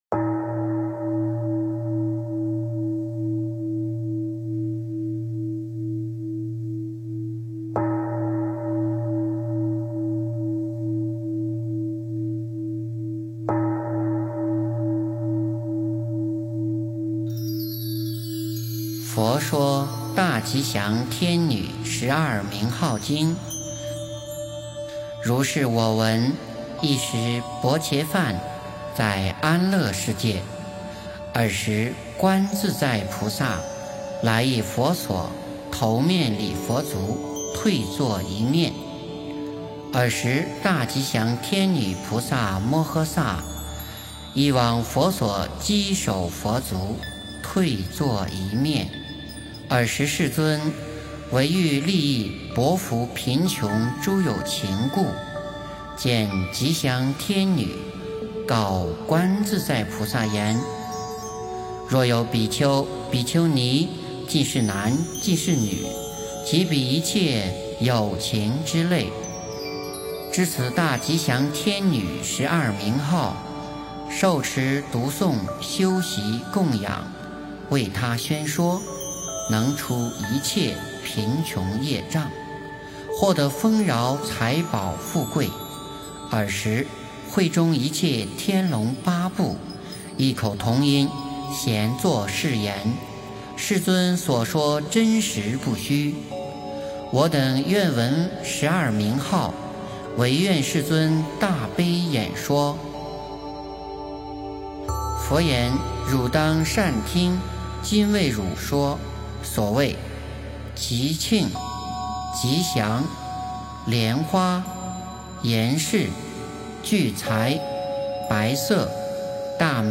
诵经
佛音 诵经 佛教音乐 返回列表 上一篇： 般若心经 下一篇： 佛母准提神咒 相关文章 娑婆界--古乐心韵 娑婆界--古乐心韵...